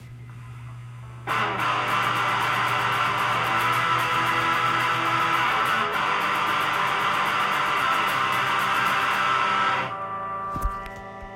---FAST STRUM---